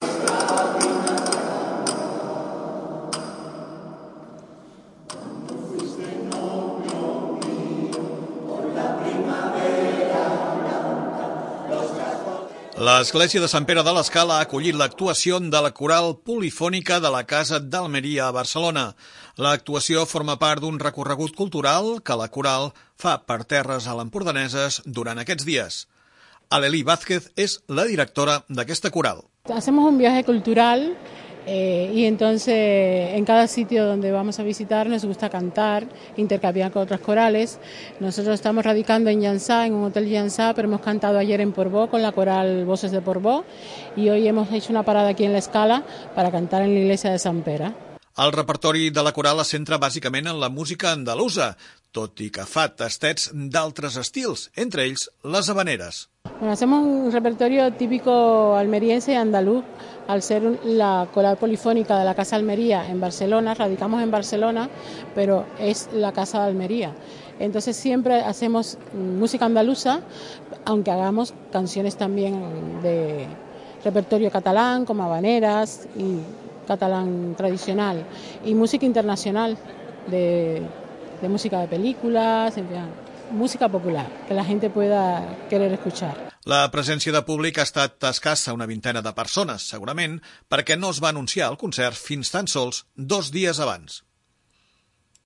A l'església de Sant Pere van oferir un repertori basat en la música andalusa, però també amb d'altres estils com l'havanera.
La presència de públic ha estat escassa, una vintena de persones, segurament perquè no es va anunciar el concert fins tant sols dos dies abans.